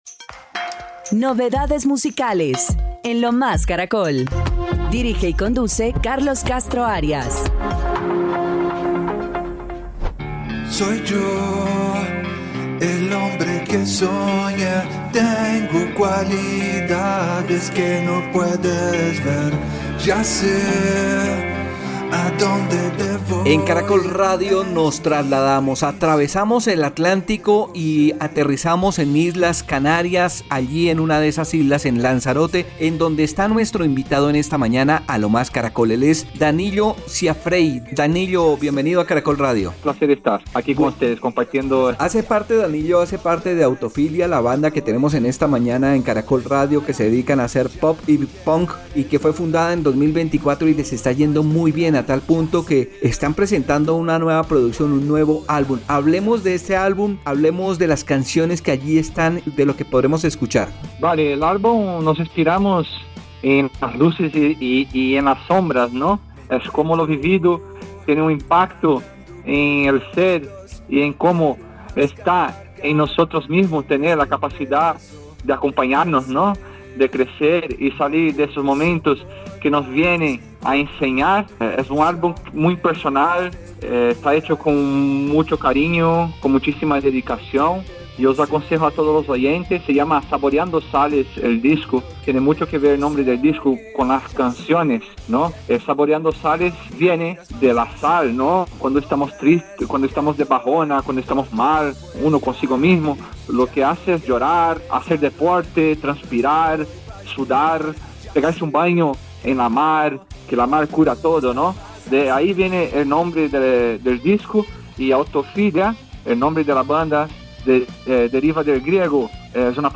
En diálogo con Lo Más Caracol